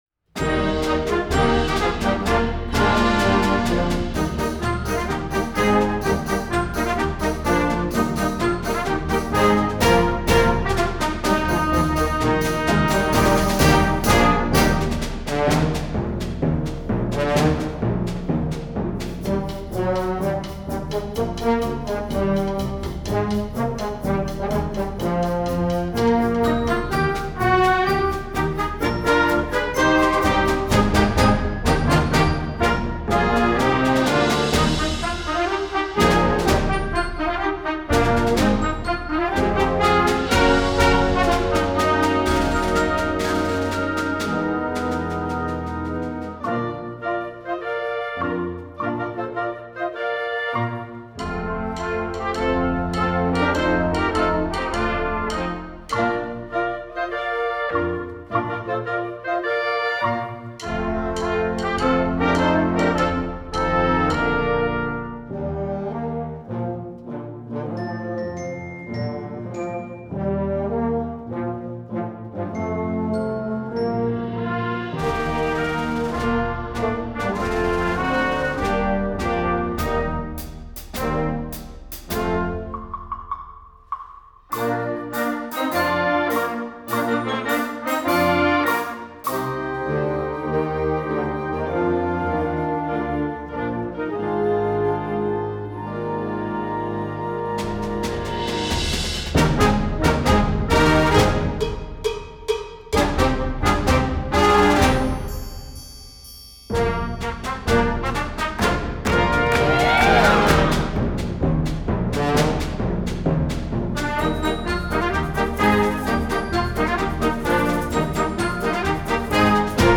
Back to the Concert Band Page